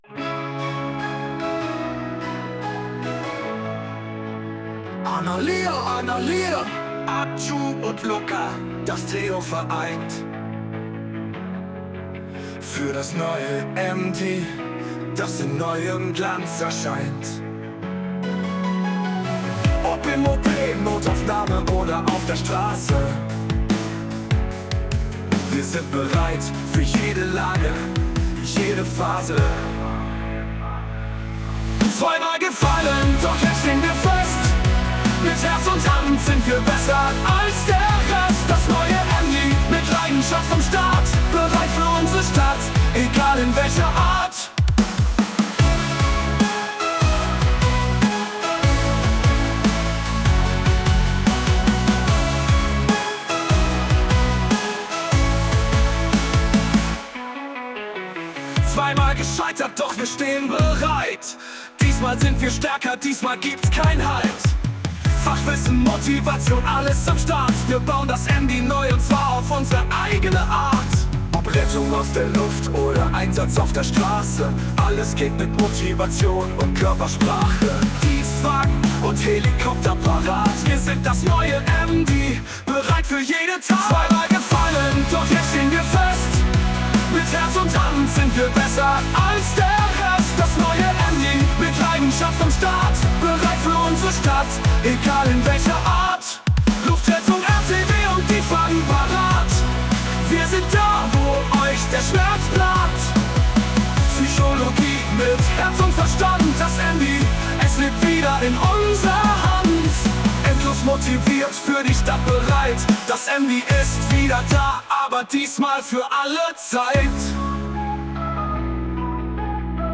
Um diesen Neustart zu feiern und ihre Entschlossenheit zu unterstreichen, haben sie einen Song herausgebracht, der die Zukunft des MD beschreibt. Der Track, mit kraftvollen Texten und einer klaren Botschaft, macht deutlich: Das MD ist zurück – stärker, besser und bereit, alles zu geben.